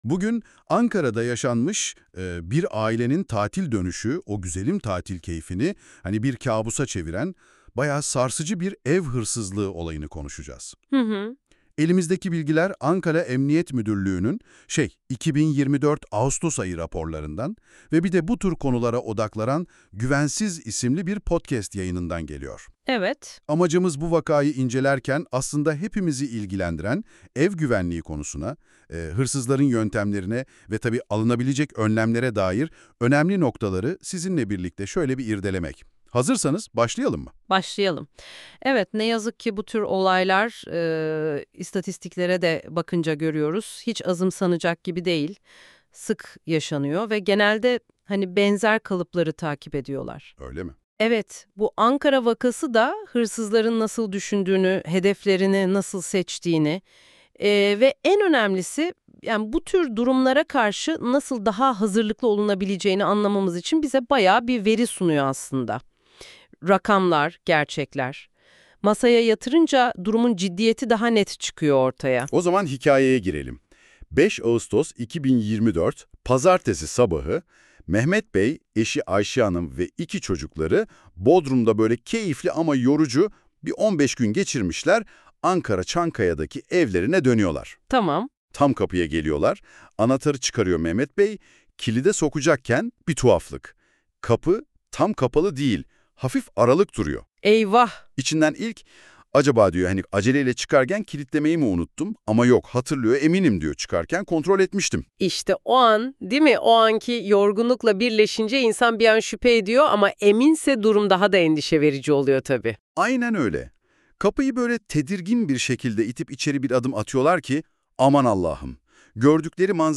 İki host